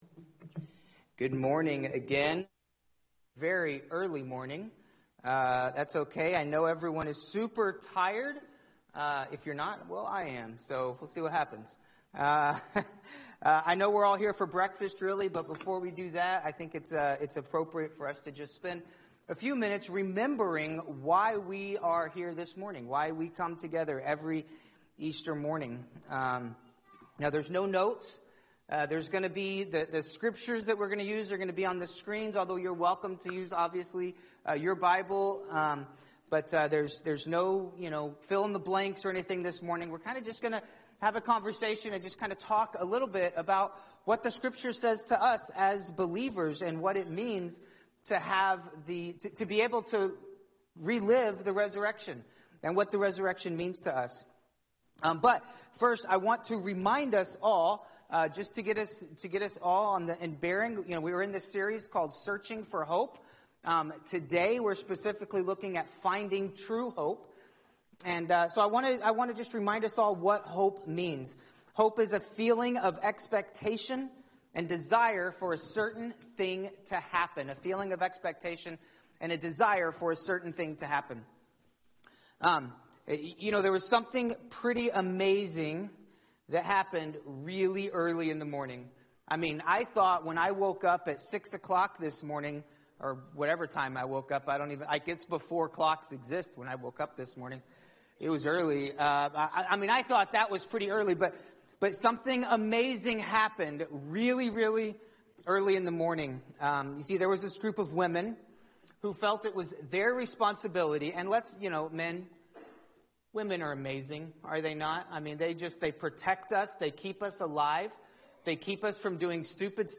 Finding True Hope (SON-rise service)